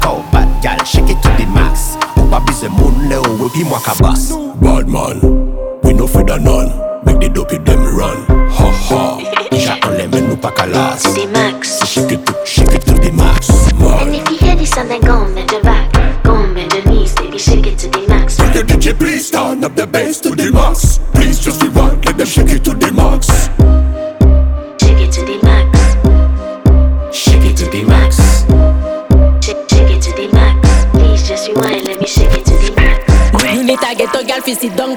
Afrobeats African